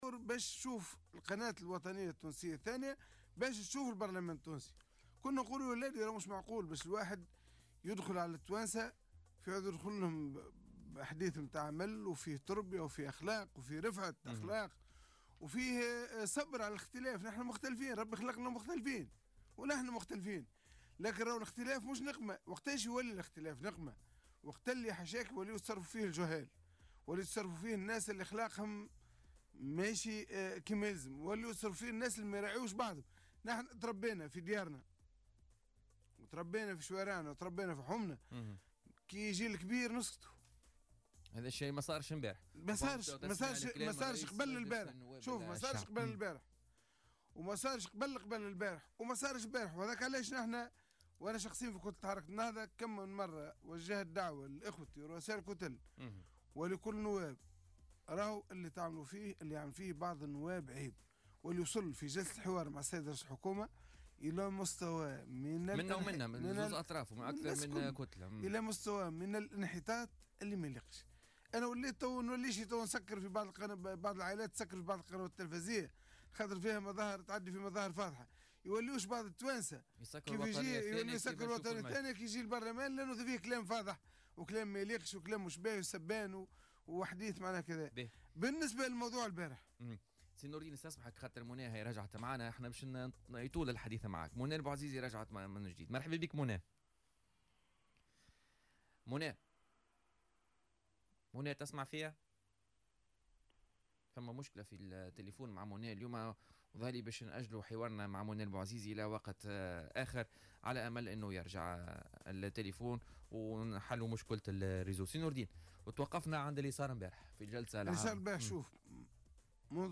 قال القيادي في حركة نداء تونس نور الدين البحيري ضيف بولتيكا اليوم الثلاثاء أن حركة النهضة كانت أول من نبهت إلى وضعية هيئة الحقيقة والكرامة و خطورة استمرار عدم سد الشغور فيها ولم يكن ذلك من باب تصفية حسابات حزبية ضيقة بل من منطلق ما تقتضيه المصلحة الوطنية.